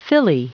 Prononciation du mot filly en anglais (fichier audio)
Prononciation du mot : filly